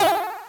jump1.ogg